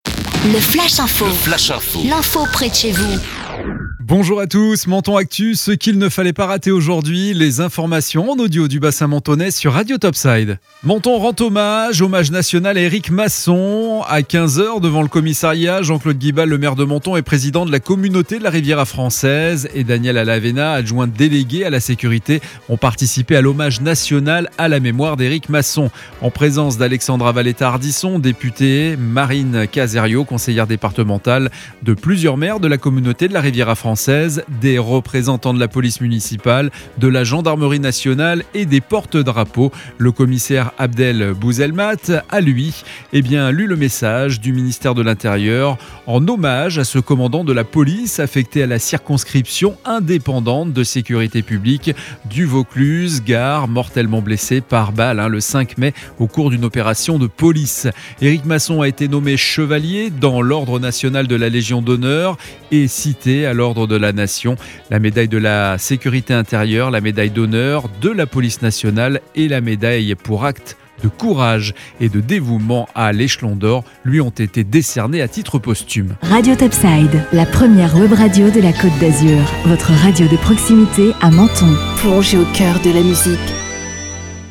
Menton Actu - Le flash info du mardi 11 mai 2021